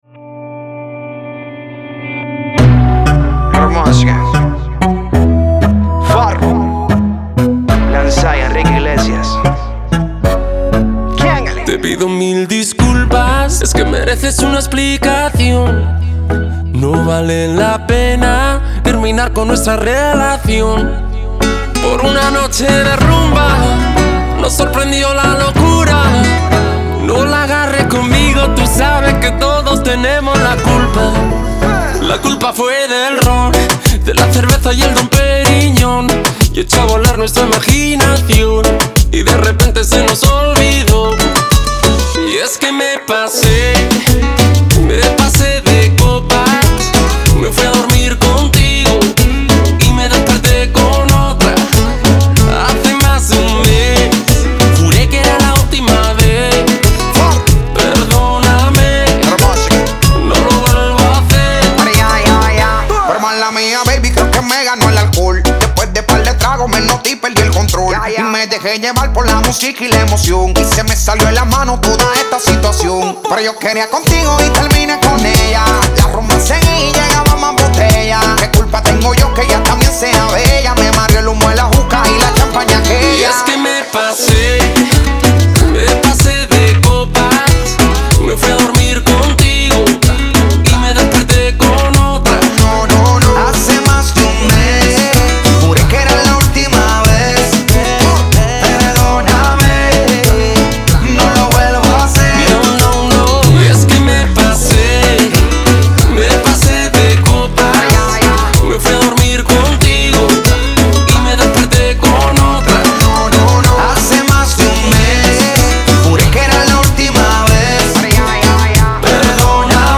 зажигательная реггетон-песня